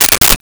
Stapler 03
Stapler 03.wav